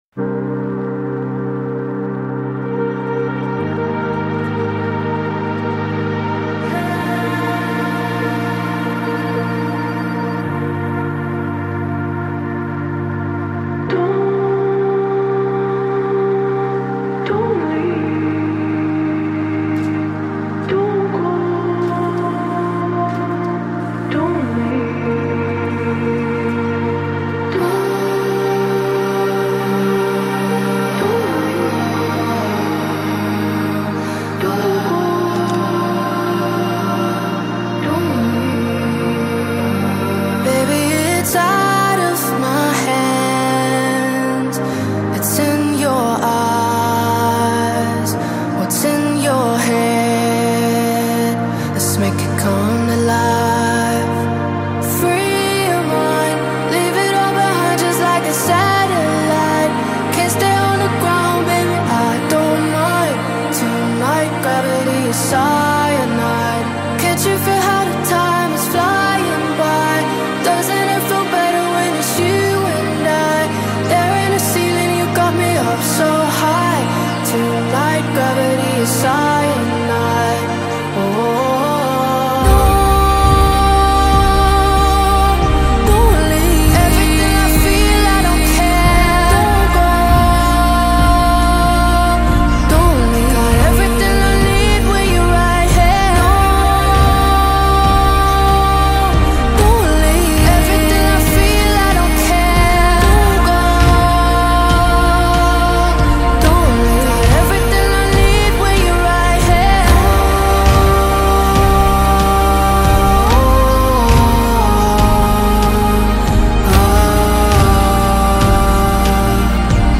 It delivers a sound that stays consistent throughout.